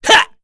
Ezekiel-Vox_Attack1_kr.wav